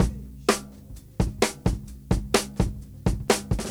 • 130 Bpm Drum Beat B Key.wav
Free drum loop sample - kick tuned to the B note. Loudest frequency: 685Hz
130-bpm-drum-beat-b-key-KLo.wav